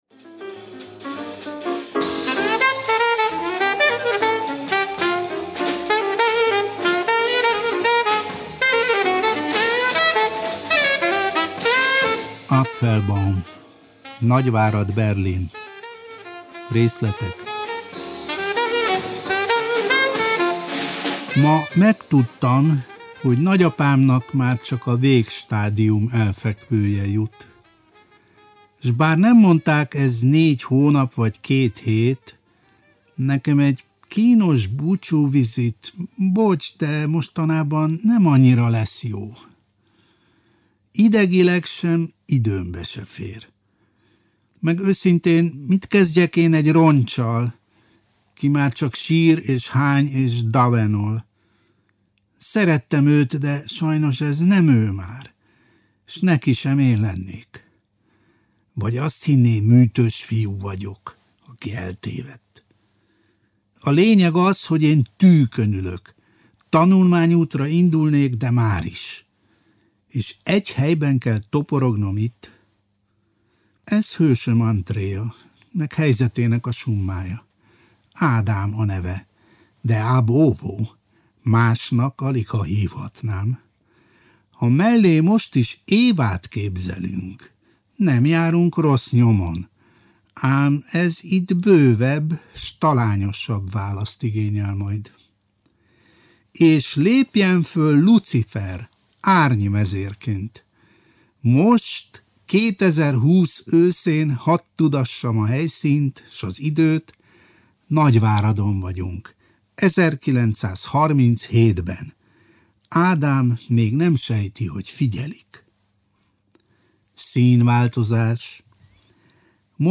Závada Pál olvas fel részleteket az Apfelbaum. Nagyvárad, Berlin című verses regényéből.